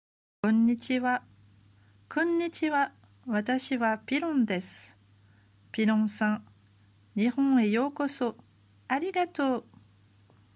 Dialogue entre Piron et la grue du Japon